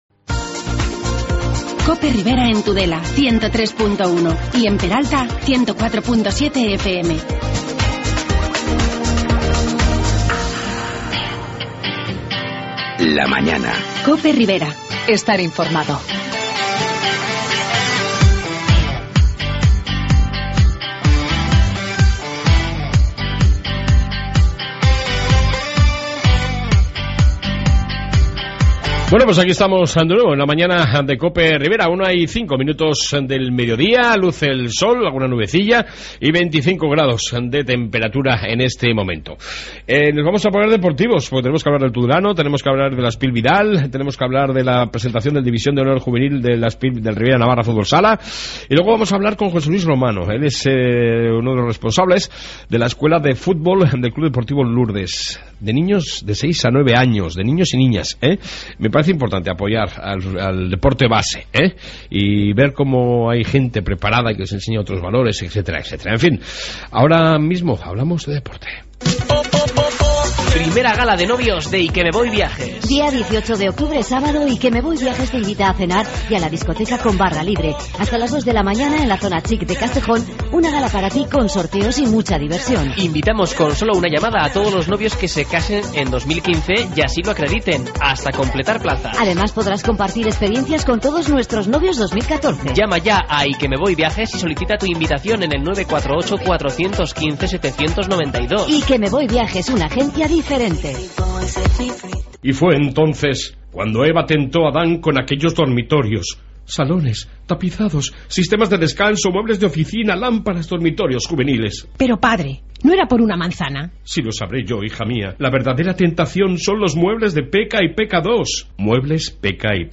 AUDIO: En esta 2 parte hablamos de deporte y entrevista sobre la escuela de Futbol del C.D Lourdes